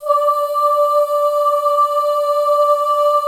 D4 FEM OOS.wav